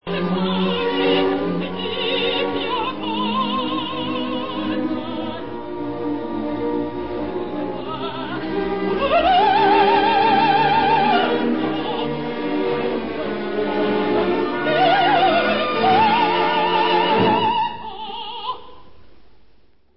Recording: OPERA